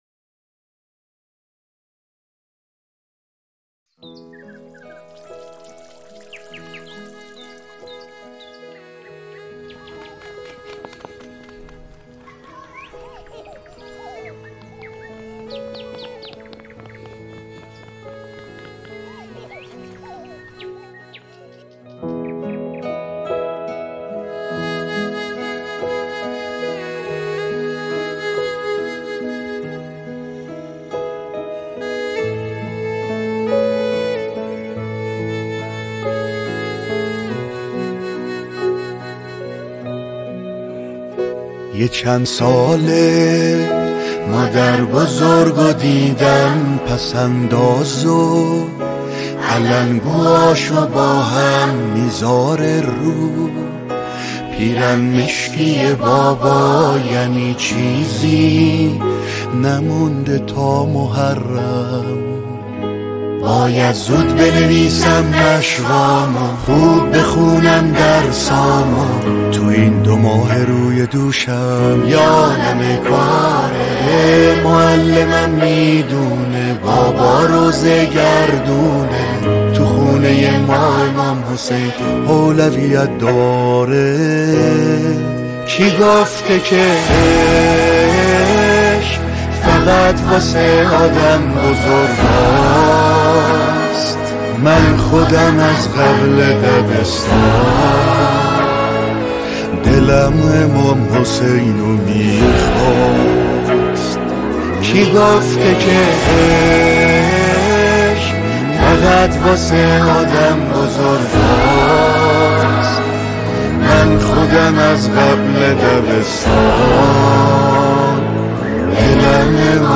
ویژه ایام سوگواری ماه محرم